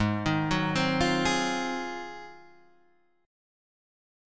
G#13 chord